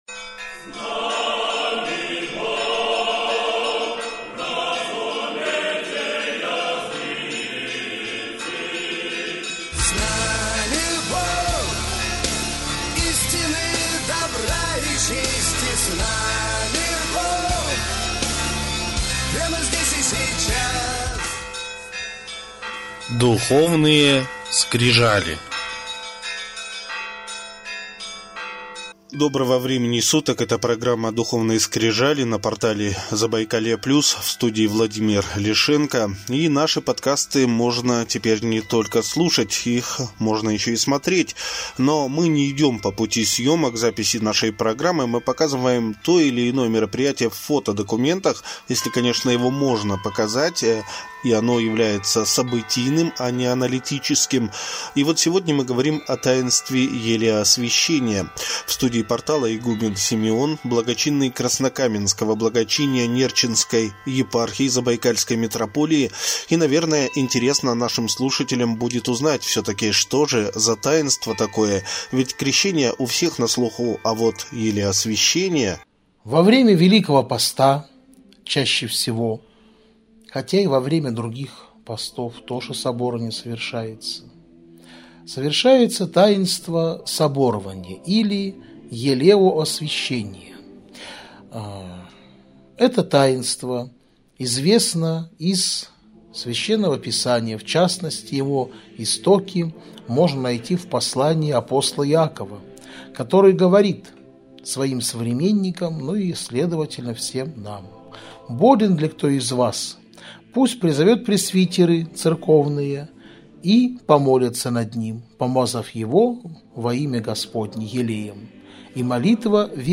Более тридцати человек приняли участие в таинстве елеосвящения что прошло в стенах православного храма “Вознесение Господне” в п. Забайкальск.